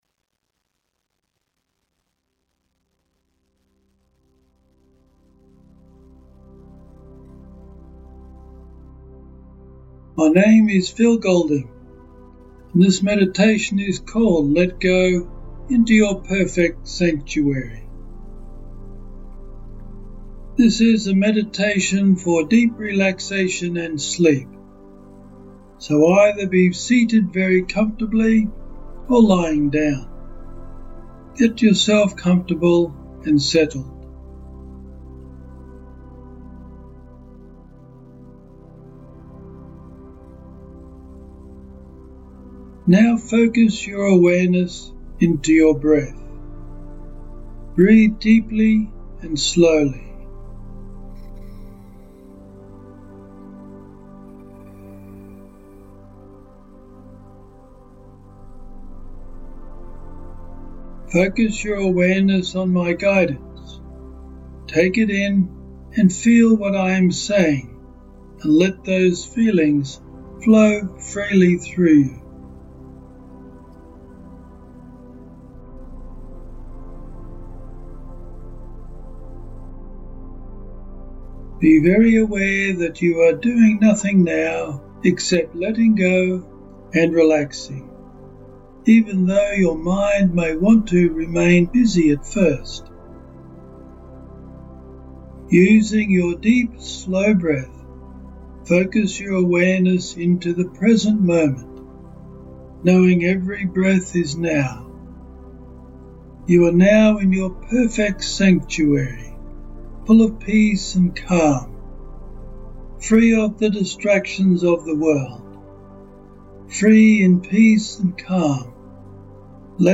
A meditation for DEEP RELAXATION AND SLEEP .